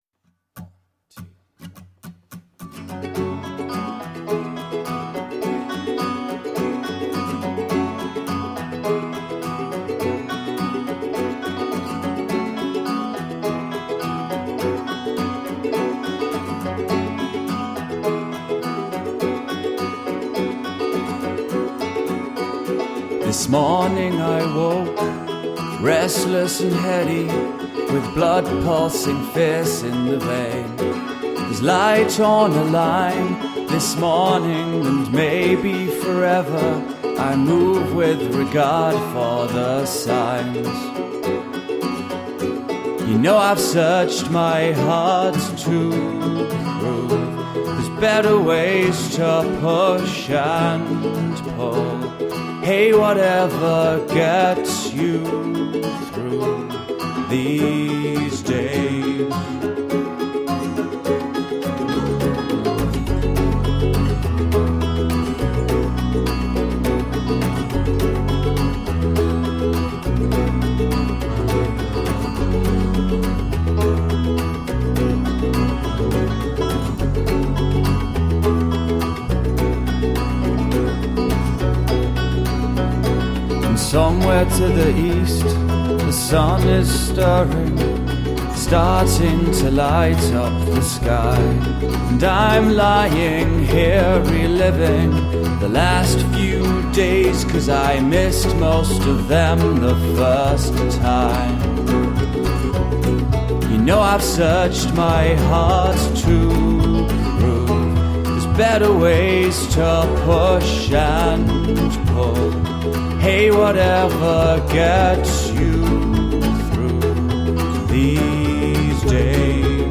Gently experimental nu-folk.
Tagged as: Alt Rock, Folk, Folk-Rock